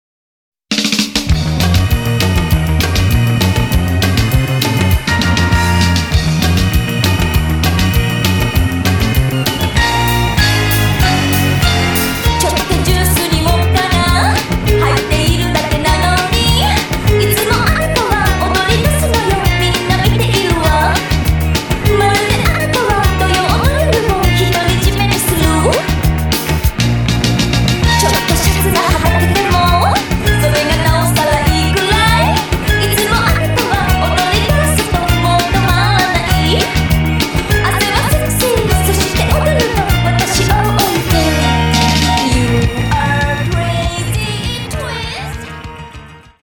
ジャンル：ポップス